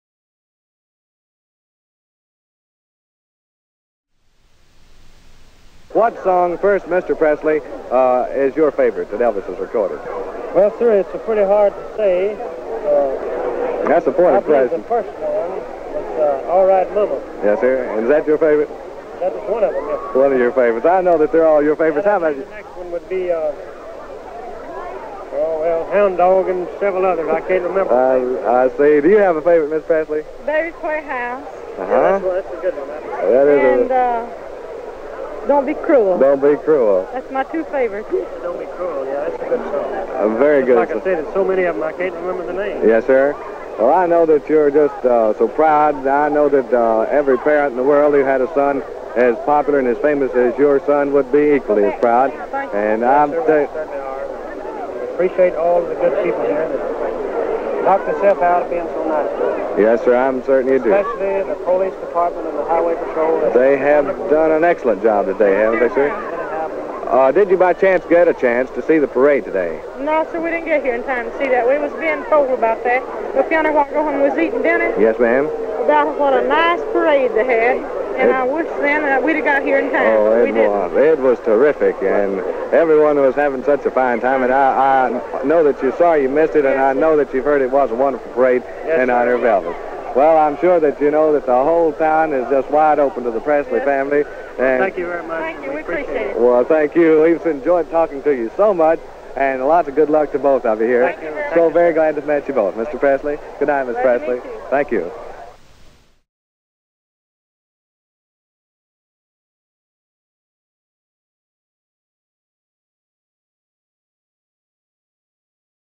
Vernon and Gladys Interview
Gladys and Vernon Presley interview.mp3